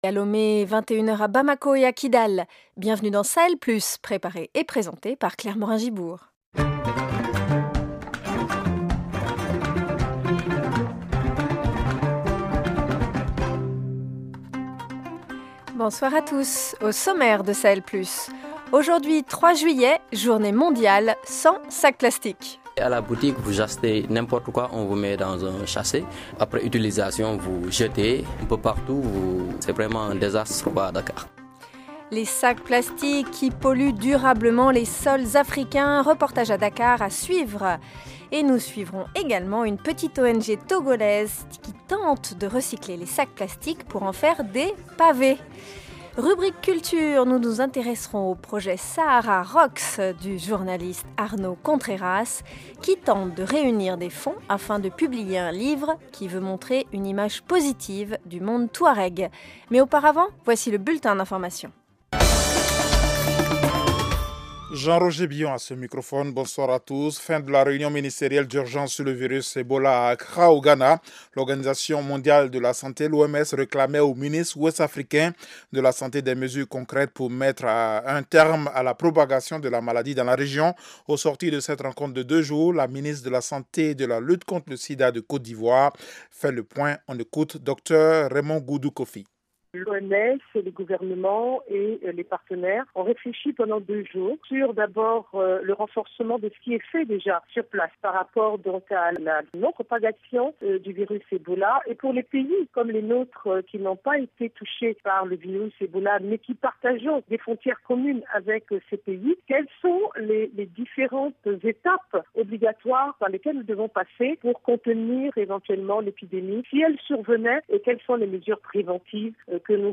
Au programme : journée mondiale sans sac plastique. Deux reportages sur ces sacs plastiques qui polluent durablement les sols africains. A Dakar, certains sénégalais prennent conscience du problème et demandent que la responsabilité de l’état soit engagée.